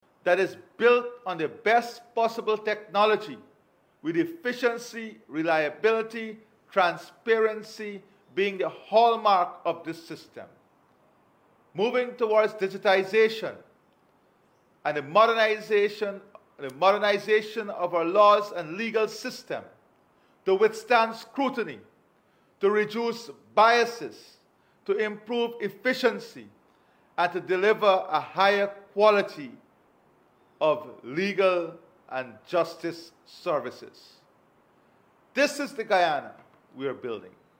In a recent address to the nation commemorating the anniversary of the March 2020 elections, President Irfaan Ali reiterated the People’s Progressive Party (PPP) government’s unwavering dedication to advancing Guyana’s prosperity and resilience.